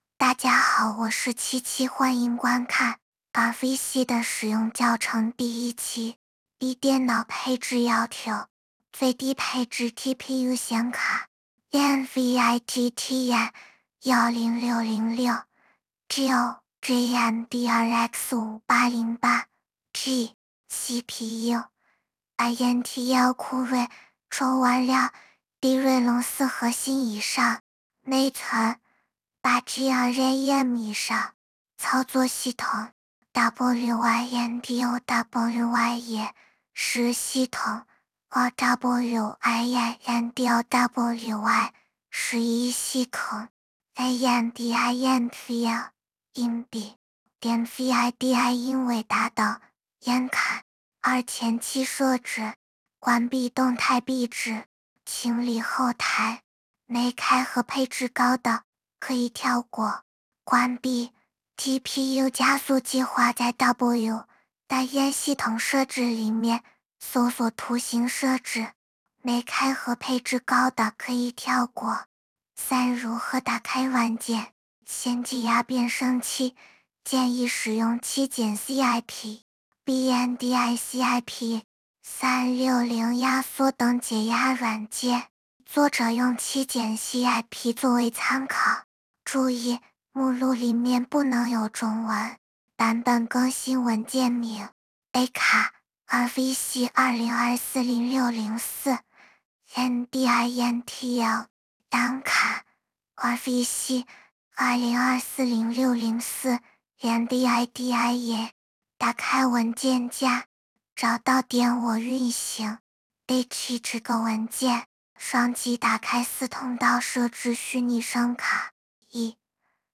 RVC变声器模型包